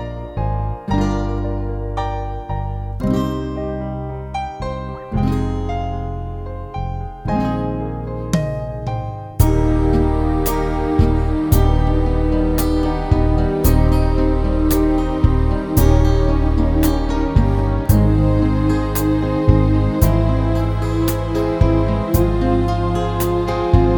no Backing Vocals Soul / Motown 5:35 Buy £1.50